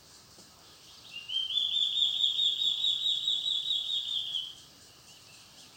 BORRALHARA-ASSOBIADORA
Nome em Inglês: Large-tailed Antshrike